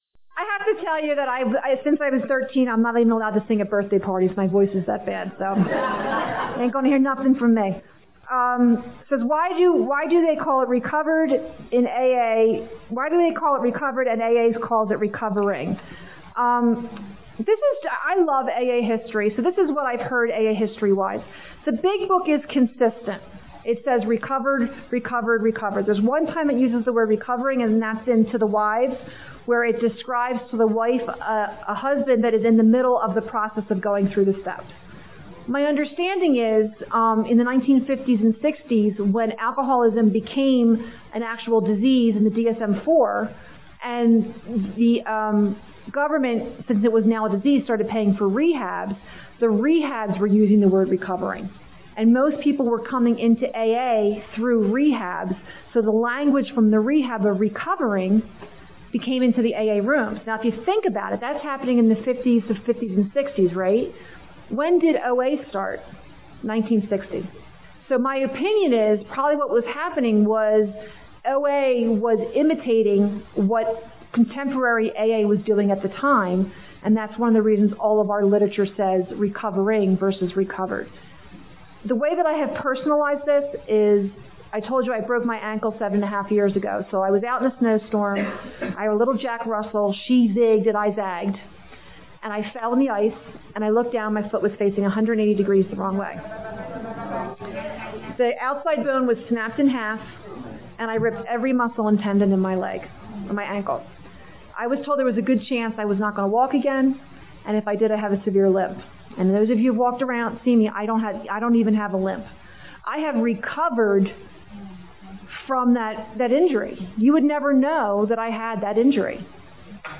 South Broadway Christian Church